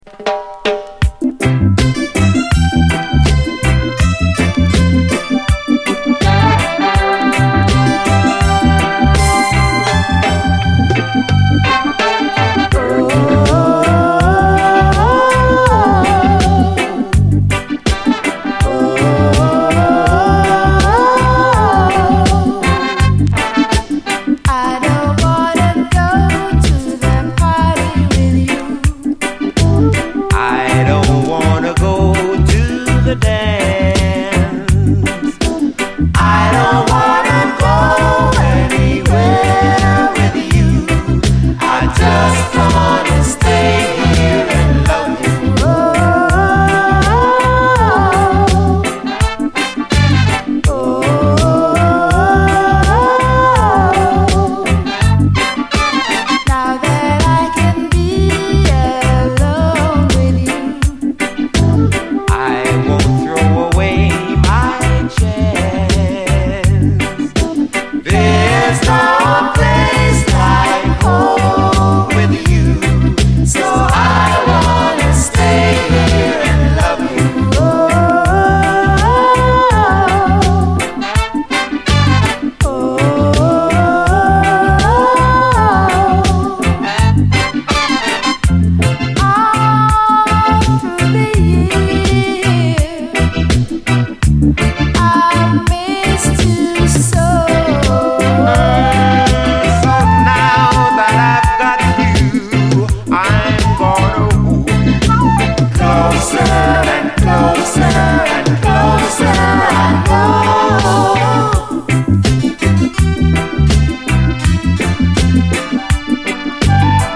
REGGAE
♪オオオオオーというコーラスが愛らしい胸キュンUKラヴァーズ！
後半はダブに接続。